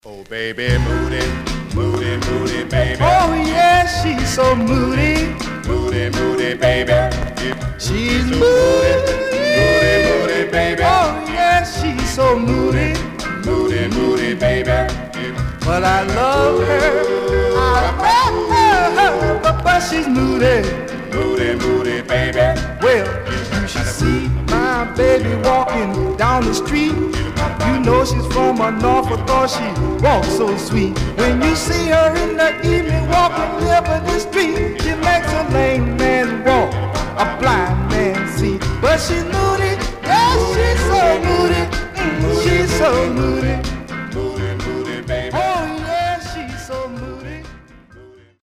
Mono
Male Black Group